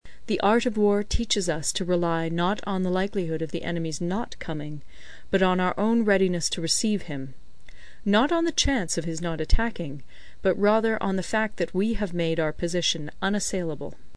有声读物《孙子兵法》第48期:第八章 九变(4) 听力文件下载—在线英语听力室